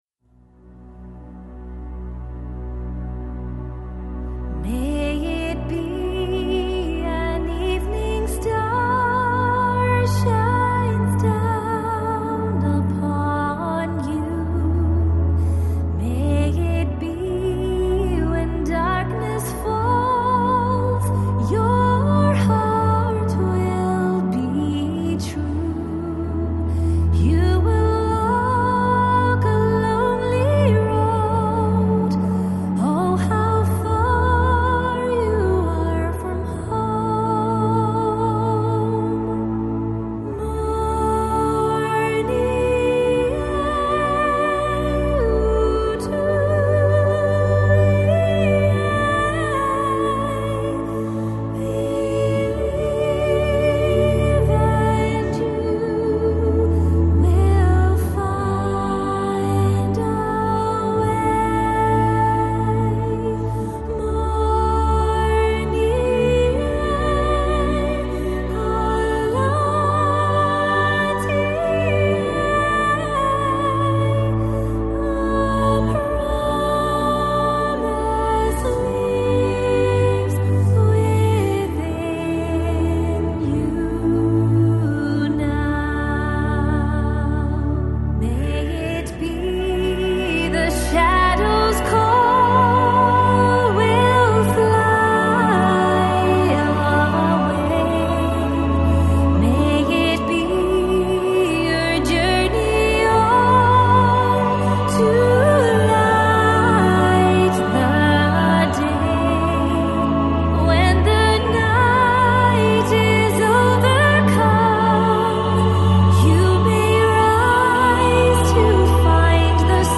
Жанр: Celtic Folk